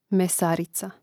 mesàrica mesarica